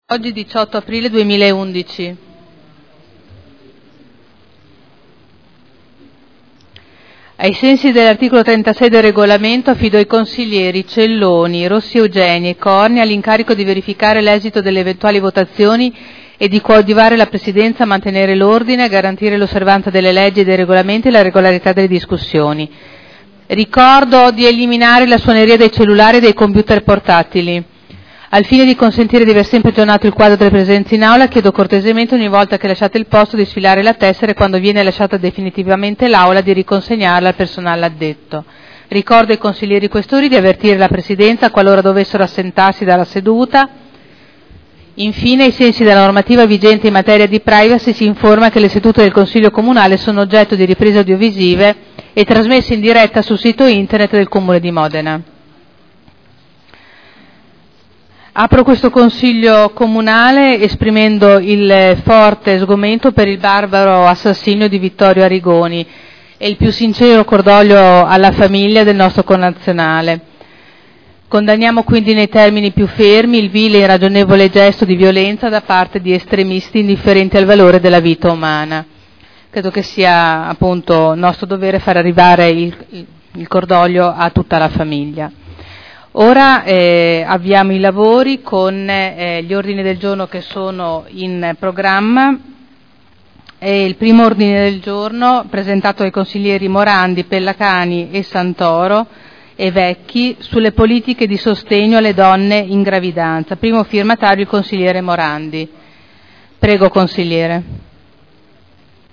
Presidente — Sito Audio Consiglio Comunale
Apertura Consiglio Comunale. Messaggio di cordoglio alla famiglia di Vittorio Arrigoni assassinato la settimana scorsa nella striscia di Gaza.